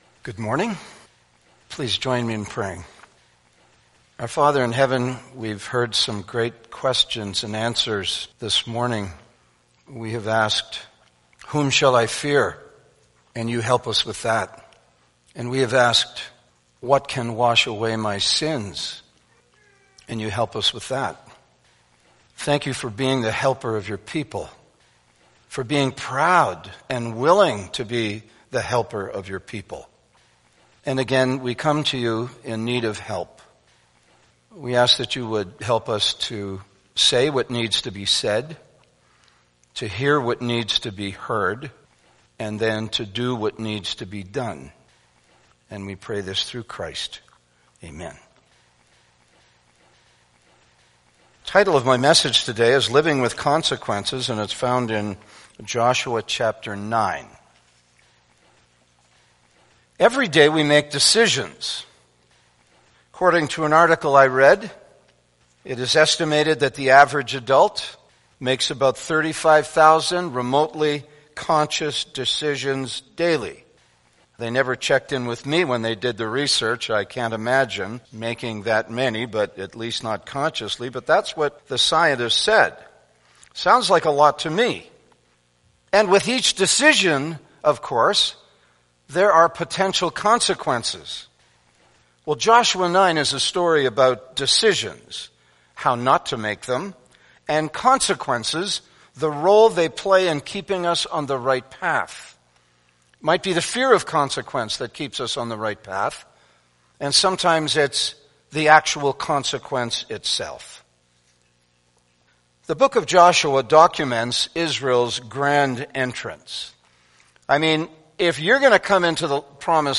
Sermons | Balmoral Bible Chapel